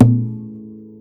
BallHit.wav